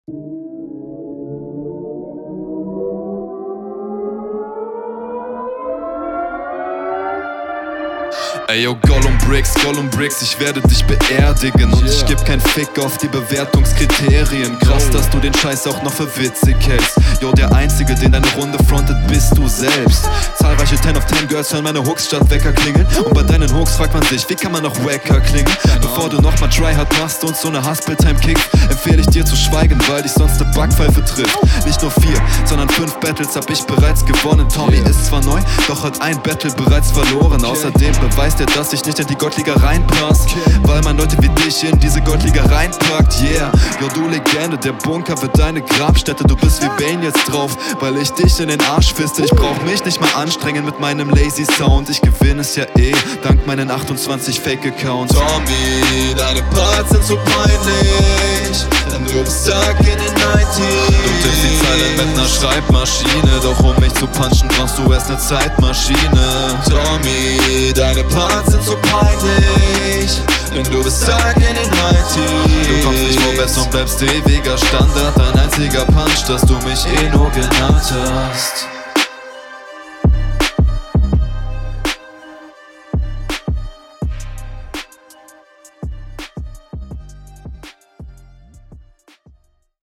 Im direkten Vergleich zum Gegner fehlt da irgendwie etwas Power und Präzision.
gesangsteil war ganz cool. passt eigentlich gut zu dem beat. der rest kommt auch etwas …